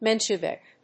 音節Men・she・vik 発音記号・読み方
/ménʃəvìk(米国英語)/